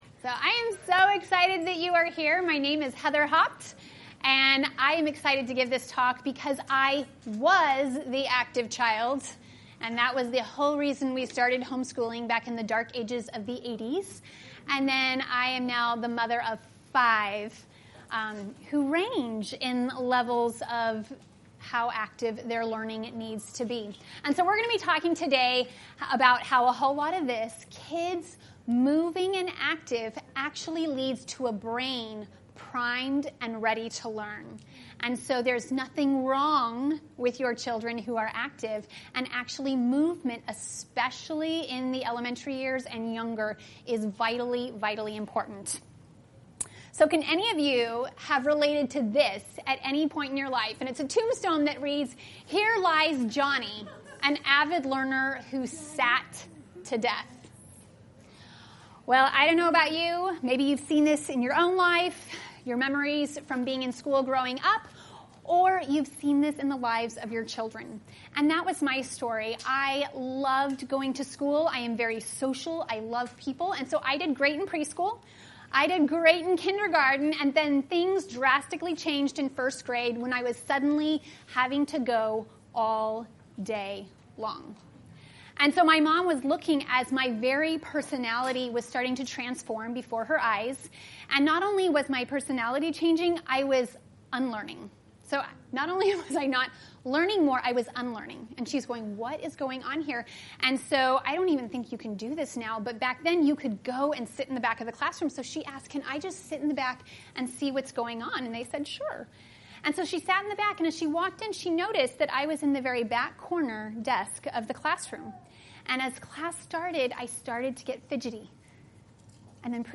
🎧Lastly, here is a podcast recording of the full session from our last year's THSC convention.